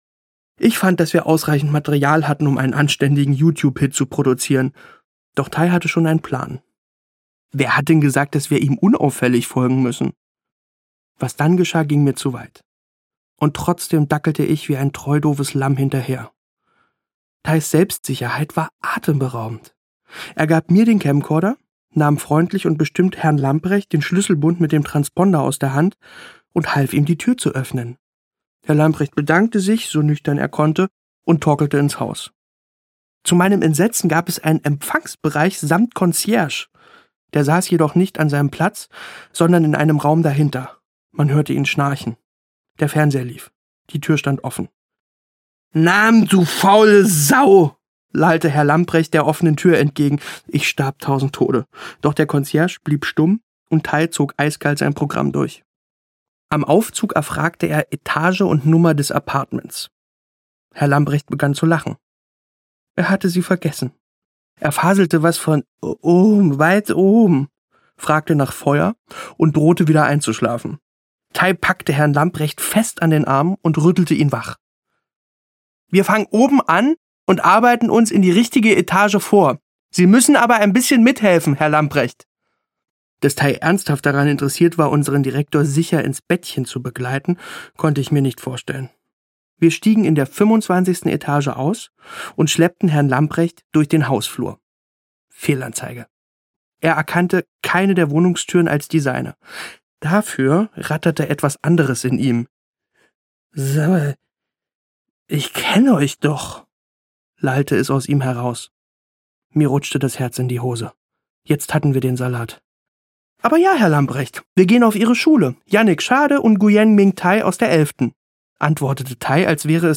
Nackt über Berlin - Axel Ranisch - Hörbuch